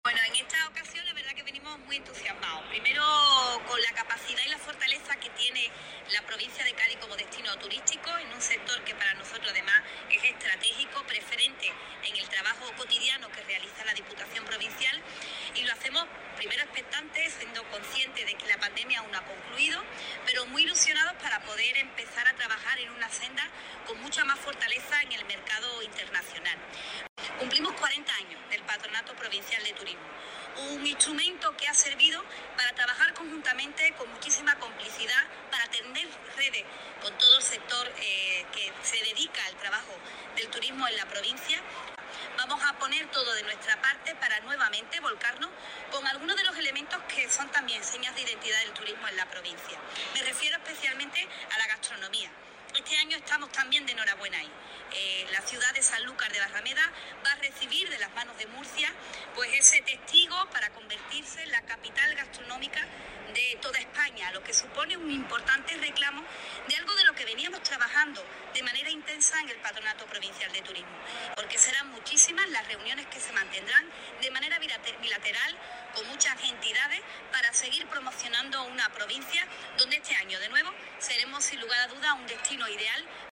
Irene-Garcia-en-Fitur-2022_a-medios.mp3